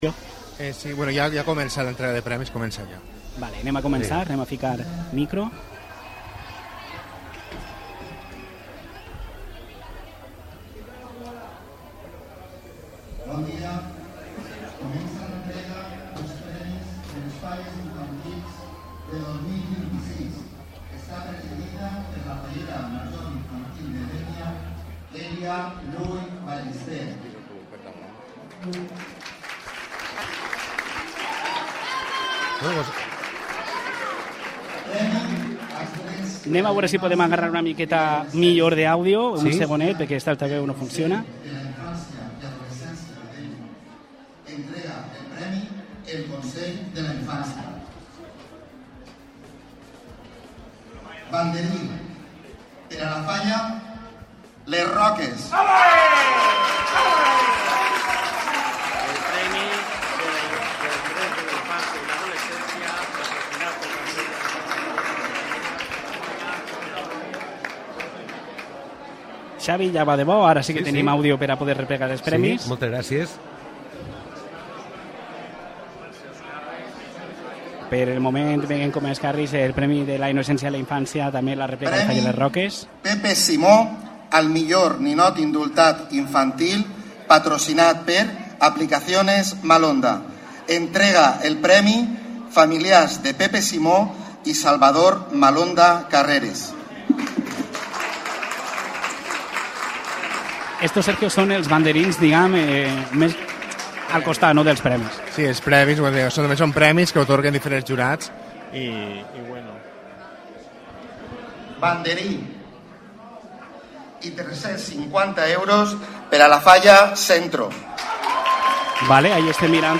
Puedes escuchar en directo el Informativo de Dénia FM de lunes a viernes a las 13.30 horas.